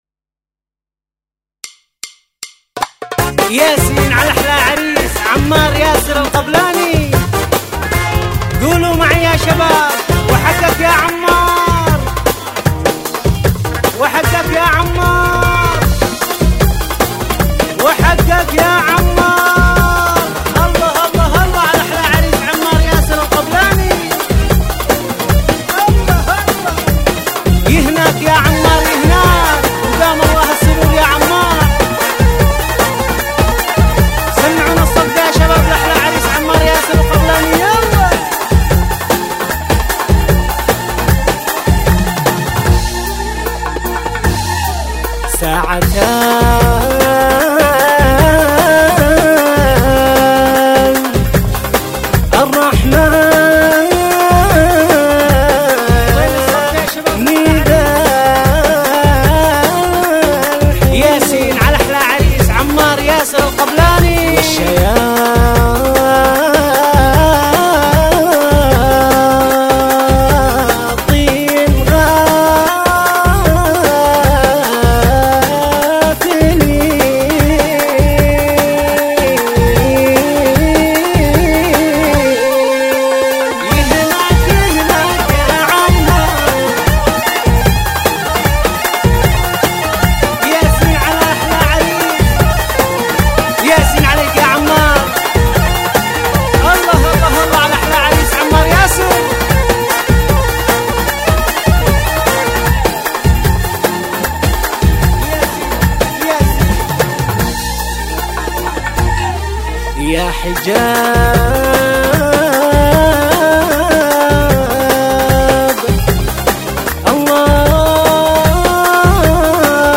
الزفة الصنعانية